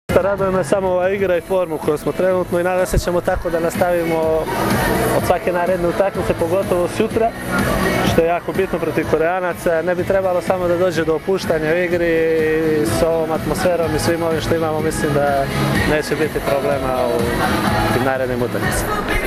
IZJAVA MILOŠA NIKIĆA